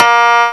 Index of /m8-backup/M8/Samples/Fairlight CMI/IIX/PLUCKED
KOTO2.WAV